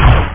Amiga 8-bit Sampled Voice
rocket.mp3